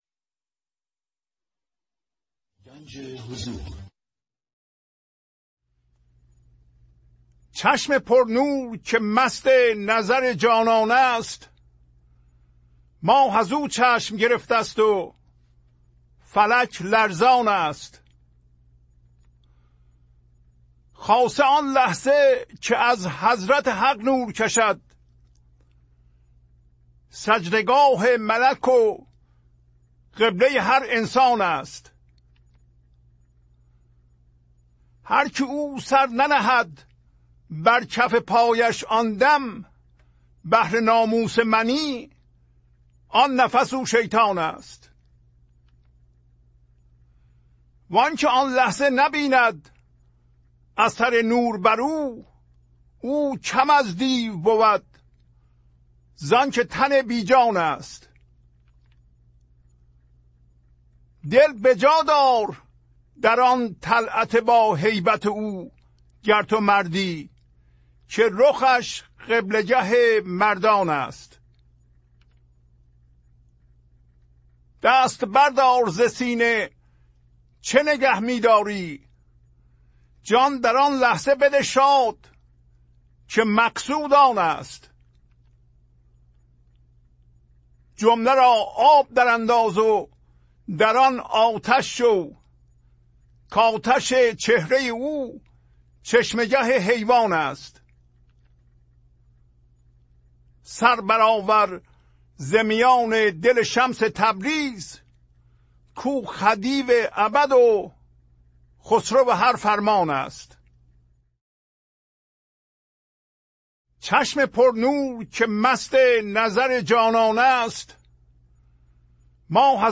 خوانش تمام ابیات این برنامه - فایل صوتی
1017-Poems-Voice.mp3